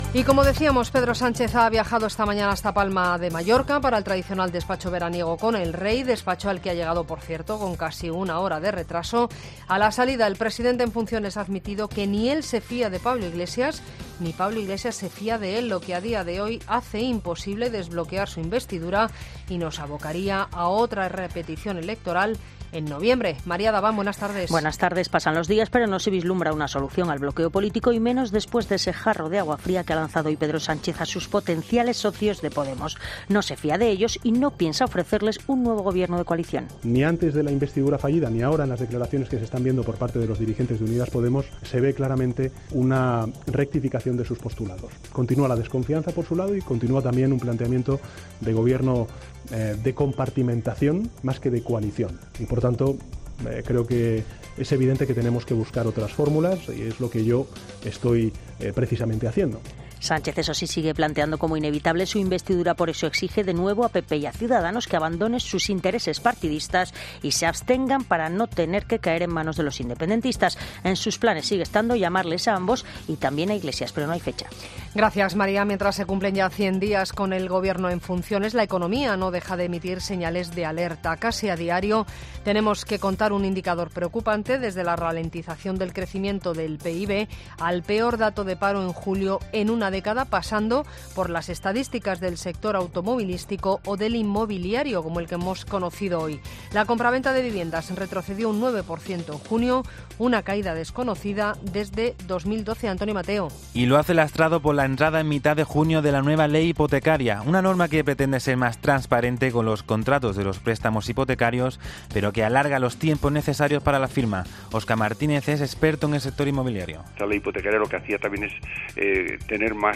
Boletín de noticias de COPE del 7 de agosto de 2019 a las 19.00 horas